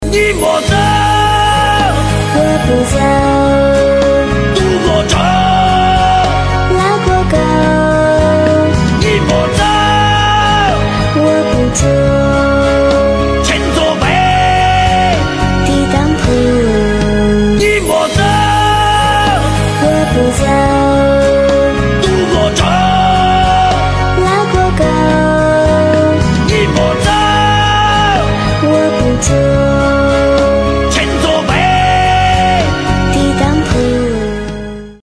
• Качество: 320, Stereo
громкие
спокойные
дуэт
китайские